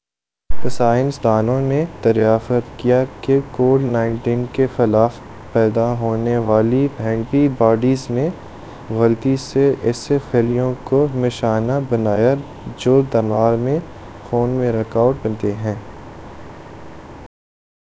Spoofed_TTS/Speaker_09/111.wav · CSALT/deepfake_detection_dataset_urdu at main